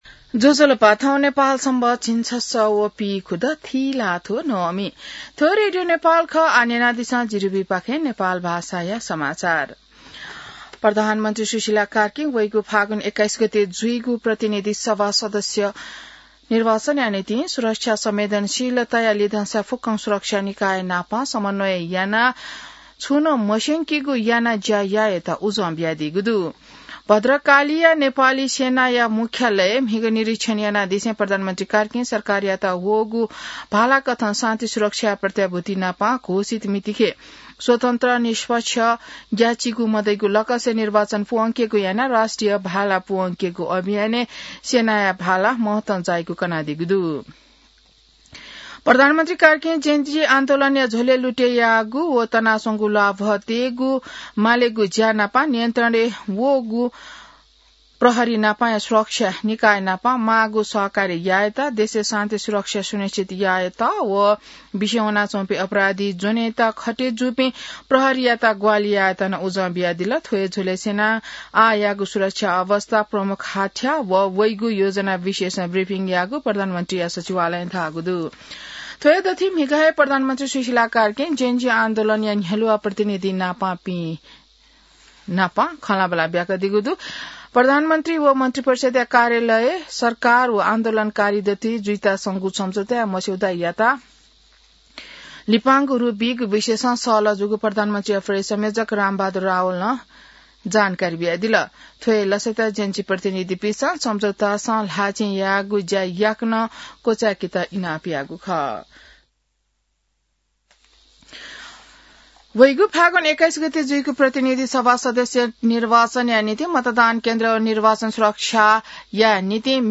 नेपाल भाषामा समाचार : १३ मंसिर , २०८२